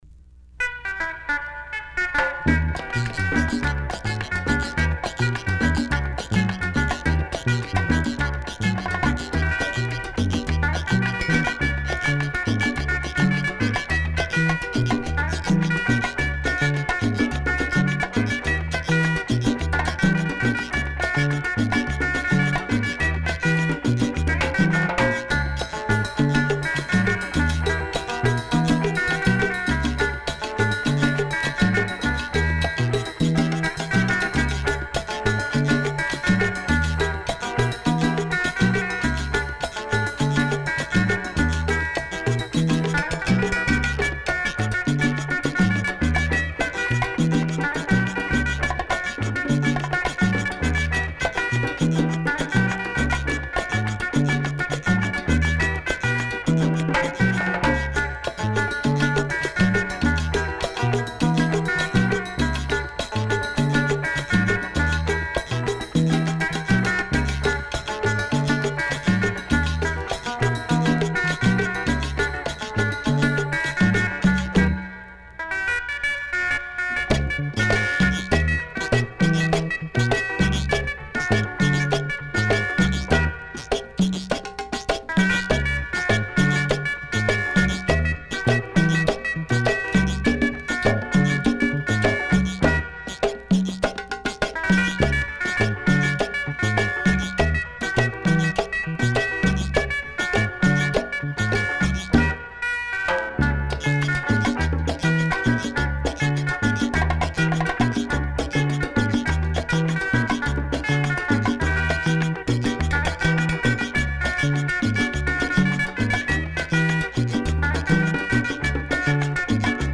Super deep chicha with a touch of psychedelic rhythms.